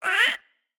Minecraft Version Minecraft Version snapshot Latest Release | Latest Snapshot snapshot / assets / minecraft / sounds / mob / ghastling / hurt2.ogg Compare With Compare With Latest Release | Latest Snapshot
hurt2.ogg